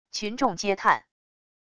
群众嗟叹wav音频